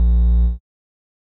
Techmino/media/sample/bass/2.ogg at bc5193f95e89b9c6dfe4a18aee2daa7ea07ff93e